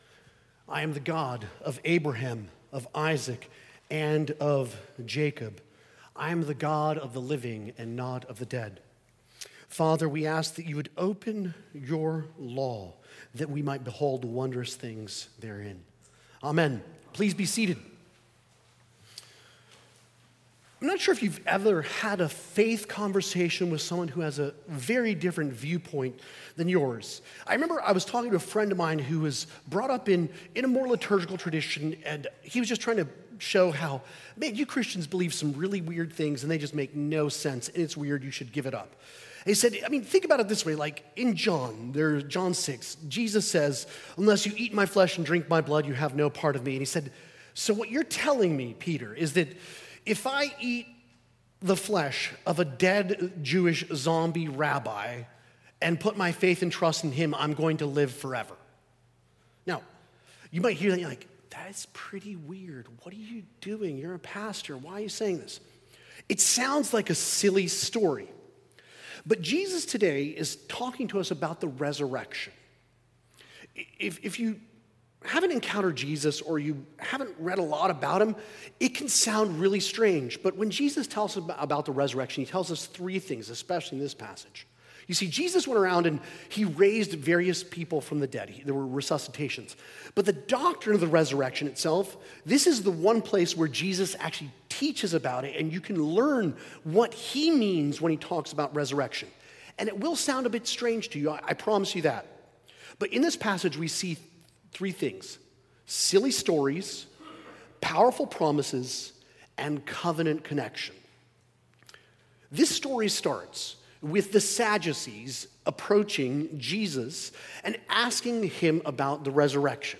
This sermon explores the resurrection as a powerful promise from God, not a silly or abstract idea, but a deeply rooted truth that transforms how we live and die.